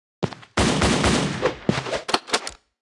Media:Sfx_Anim_Ultra_Shelly.wav 动作音效 anim 在广场点击初级、经典、高手和顶尖形态或者查看其技能时触发动作的音效